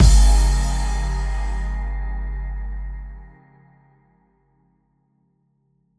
Synth Impact 16.wav